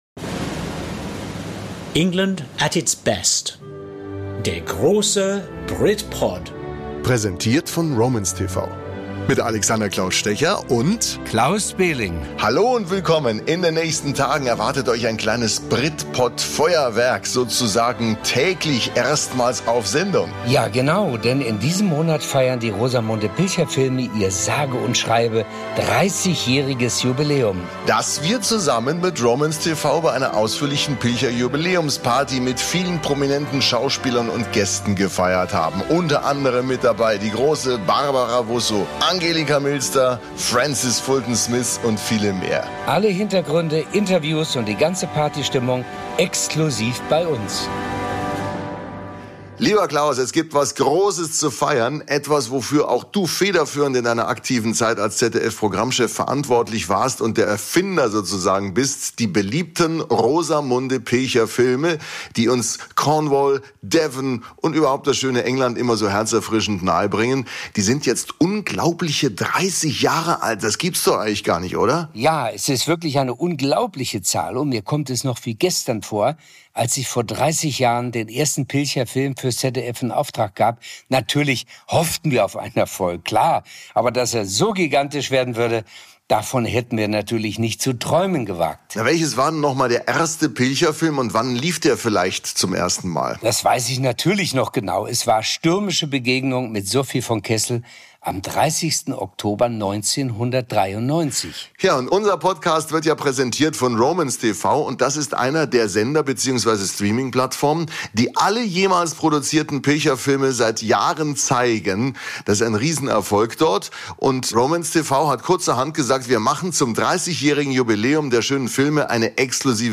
Beschreibung vor 2 Jahren Seehaus im Englischen Garten, München: An einem warmen Abend im September kommen Schauspieler, Produzenten, Autoren und Filmemacher zusammen und feiern ein Stück TV-Geschichte: 30 Jahre Rosamunde Pilcher!